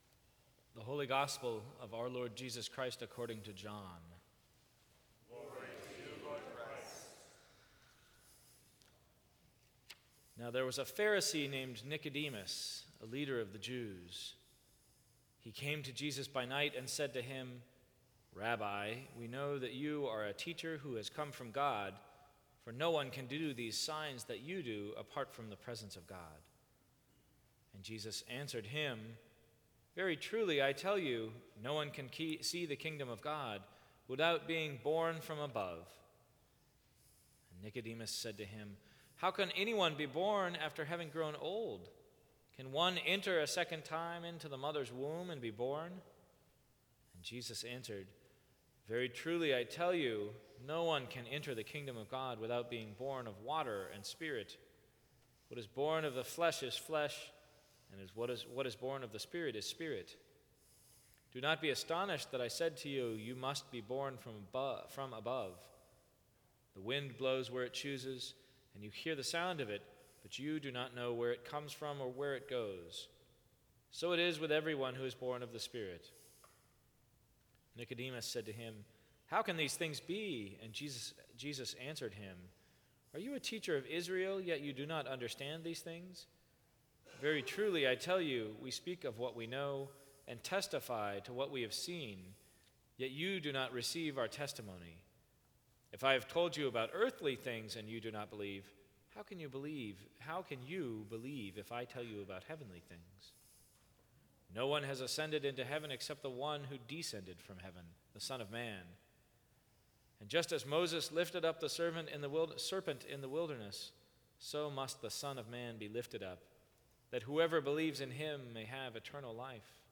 Sermons from St. Cross Episcopal Church The Power of the Trinity Sep 25 2015 | 00:14:43 1x 00:00 / 00:14:43 Subscribe Share Apple Podcasts Spotify Overcast RSS Feed Share Link Embed